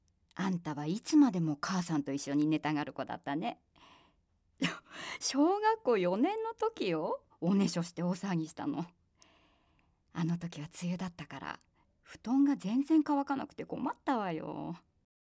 セリフ@
ボイスサンプル